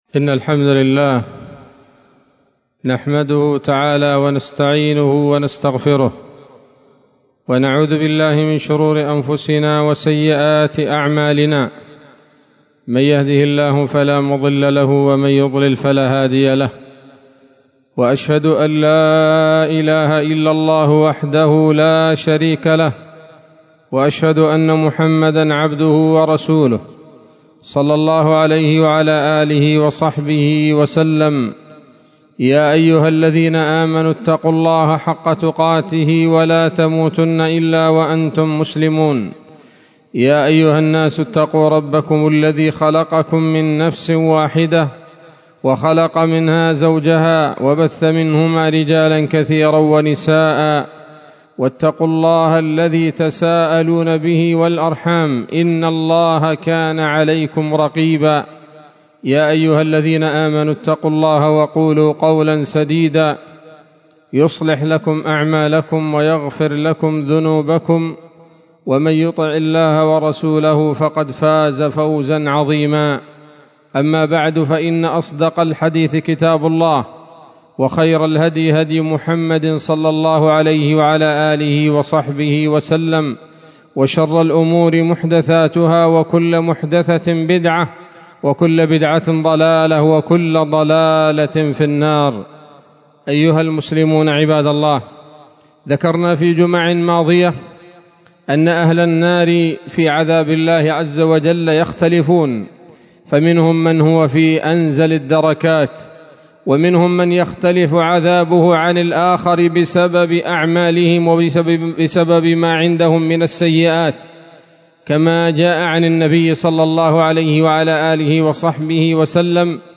خطبة جمعة بعنوان: (( ألوان من عذاب أهل النار )) 20 صفر 1444 هـ، دار الحديث السلفية بصلاح الدين